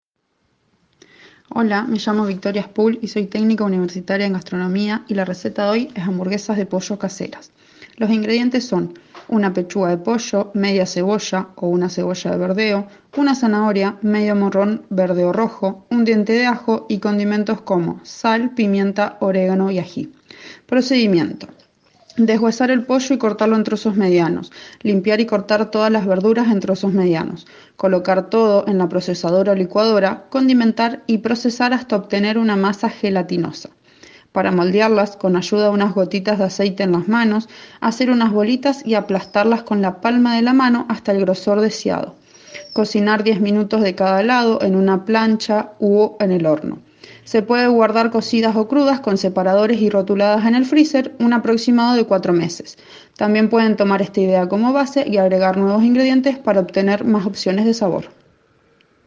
Audio receta de las hamburguesas de pollo caseras